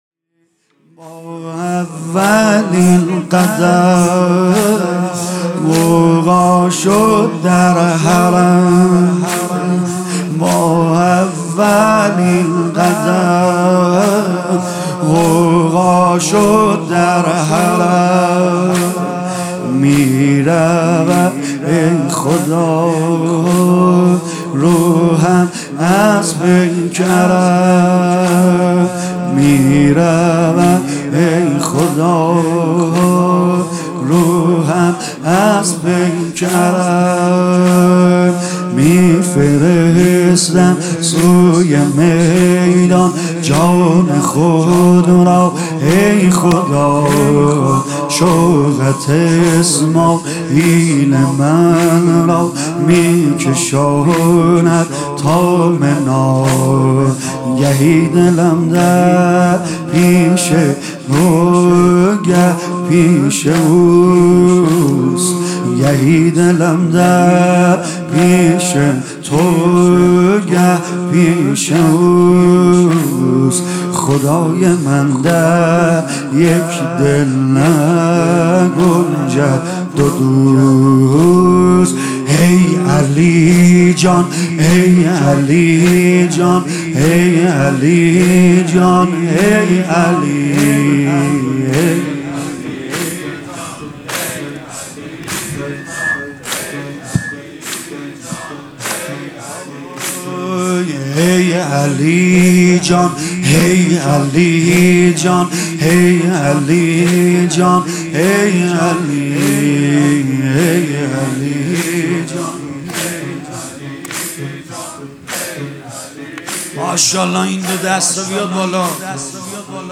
شب هفتم محرم ۹۹ - هیئت فدائیان حسین